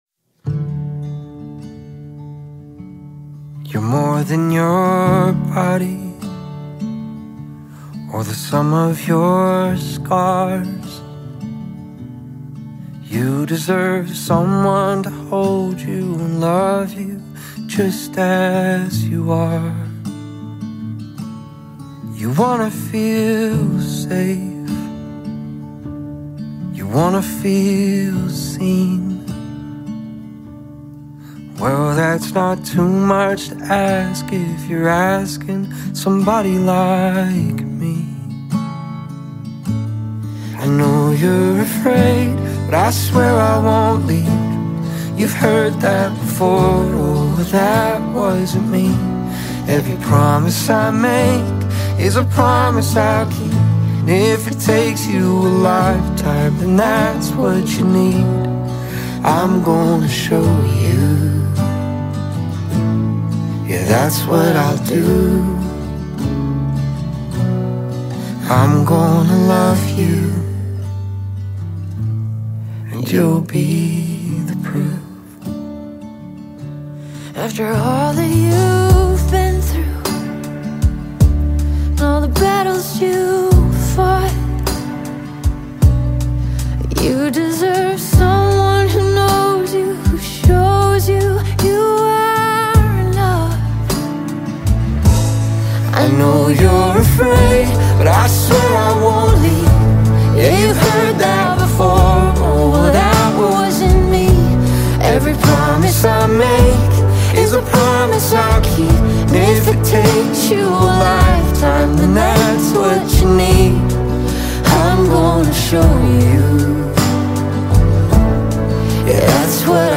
smooth vocals
rich production